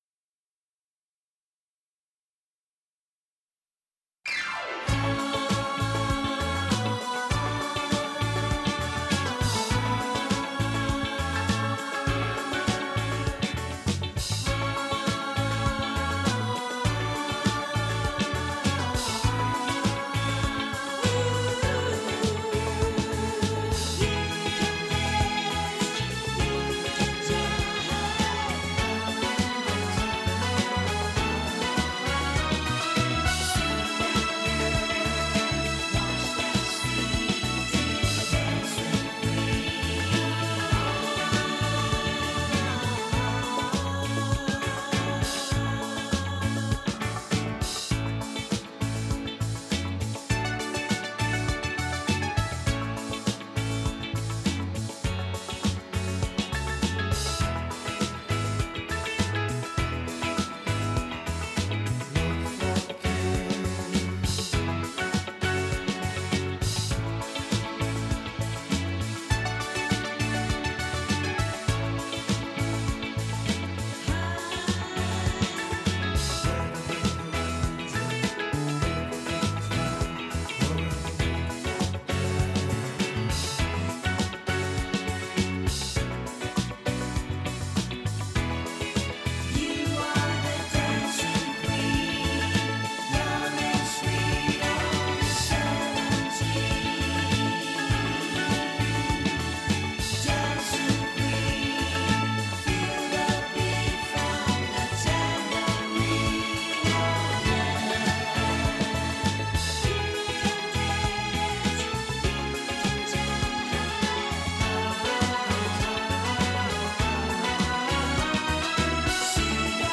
4 VOCALS